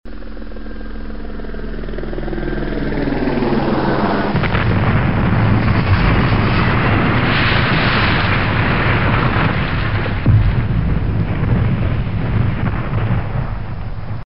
Звуки вертолёта
Грохот падающего вертолета о скалы